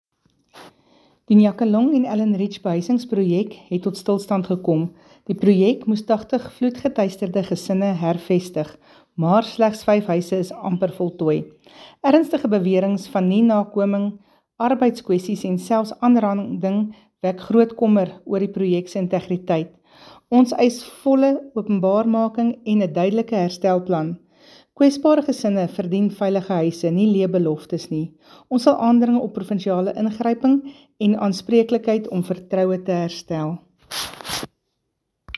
Afrikaans soundbites by Cllr Jessica Nel and